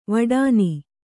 ♪ vadāni